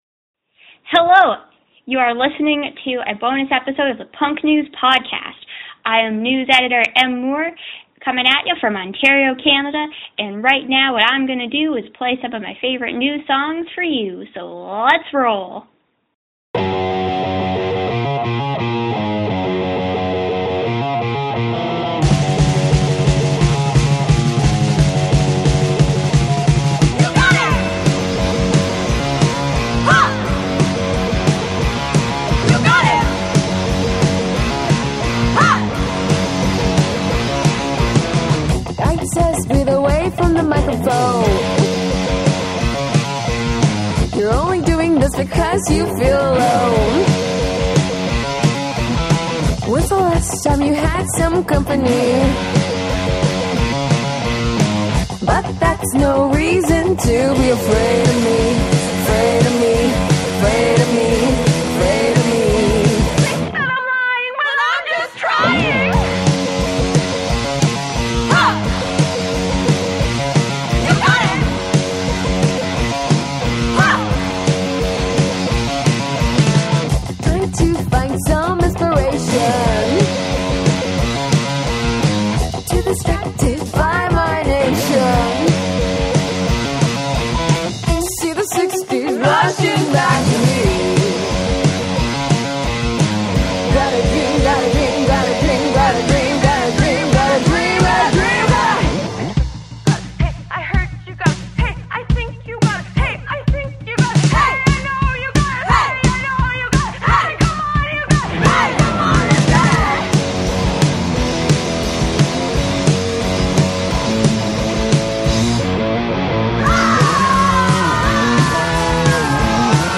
Metal, punk, pop (?), and everything in between!